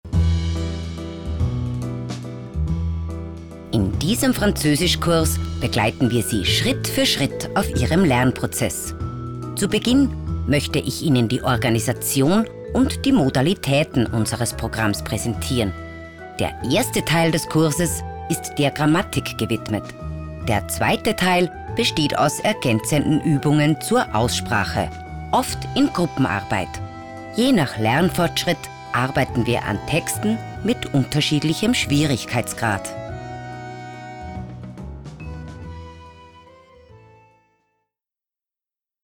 E-Learning
Ich führe Ihre Kunden mit sachkundigem Klang, deutlicher Aussprache und Motivation durch den Lerninhalt.
Meine Frauenstimme hat Seltenheits- bzw. Wiedererkennungswert, da ich eine tiefe, markante und seriöse Klangfarbe habe.